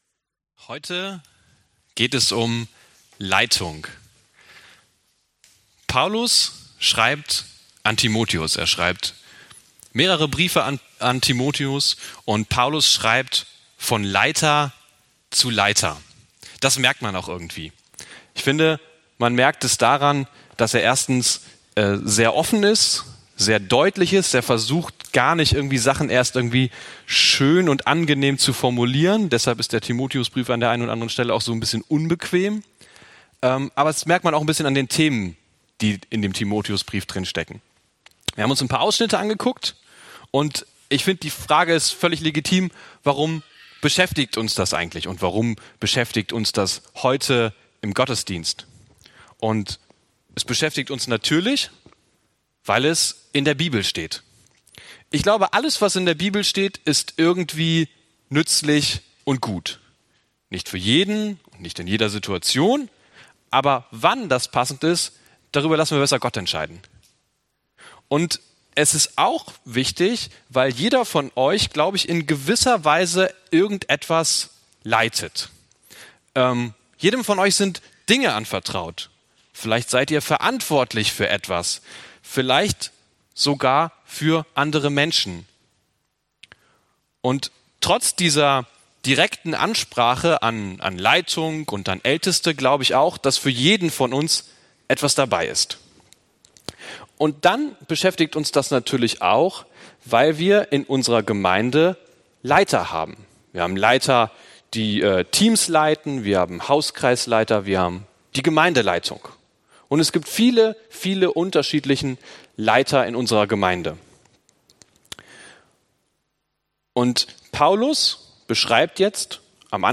Oktober 2020 verantwortlich leiten Prediger(-in)